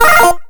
PowUp_04.mp3